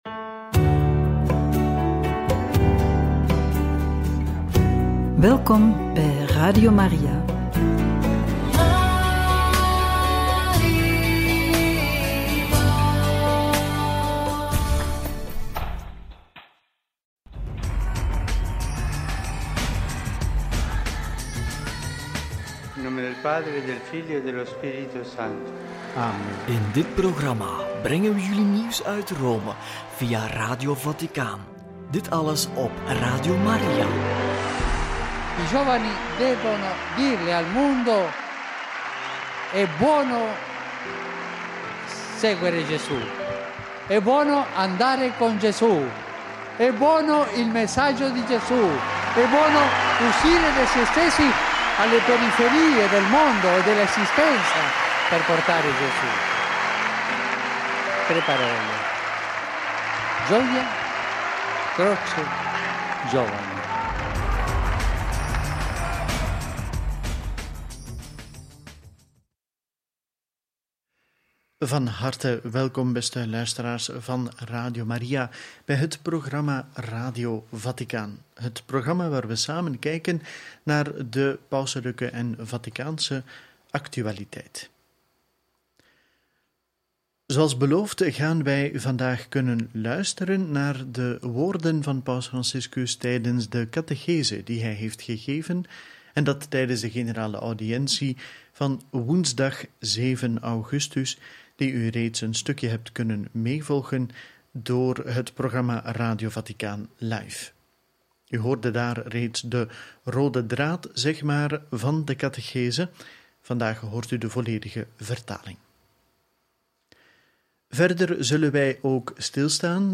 Homilie Kerkwijdingsfeest Santa Maria Maggiore – Generale audiëntie 7/8 – Pauselijk schrijven over ‘het belang van literatuur in de vorming’ – Deel 2 – Radio Maria